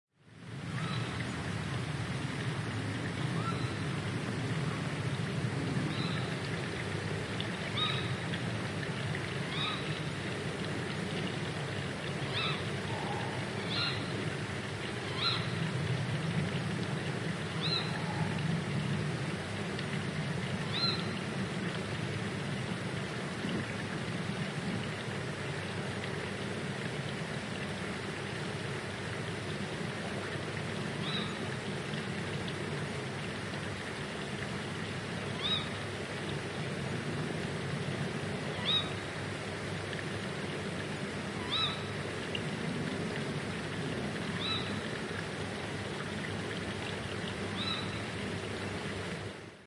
Water Sound Button - Free Download & Play